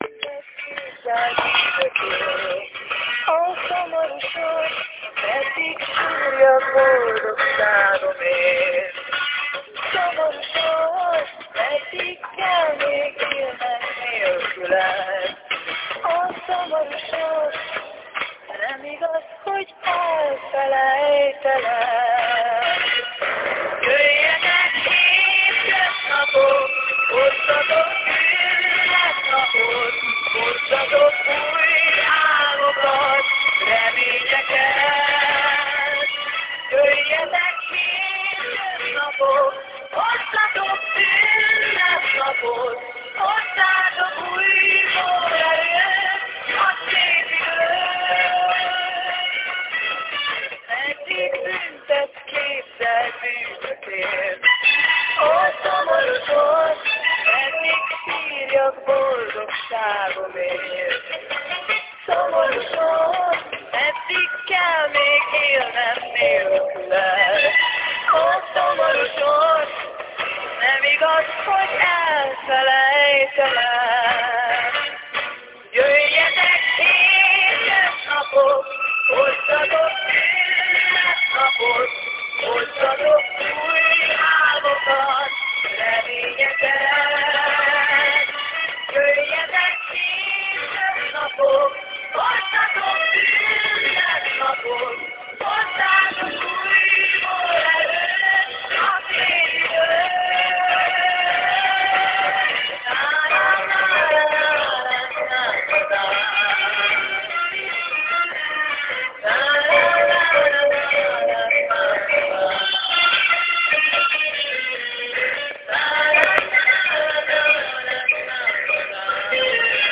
Какие-то венгры поют.